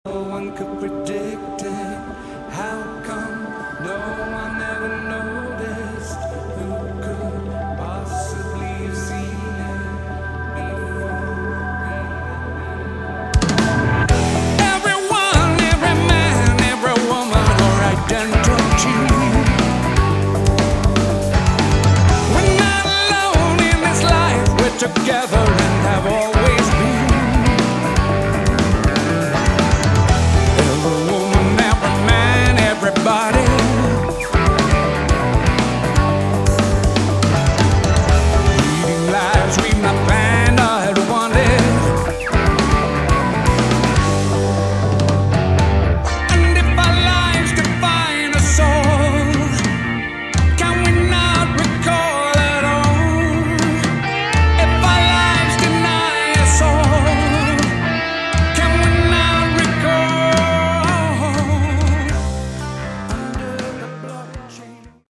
Category: Prog Rock
vocals
guitars, bass
drums
keyboards, Hammond organ, piano
Nice retro prog rock.